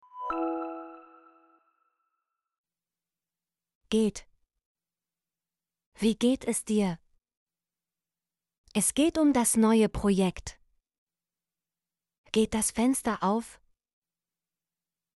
geht - Example Sentences & Pronunciation, German Frequency List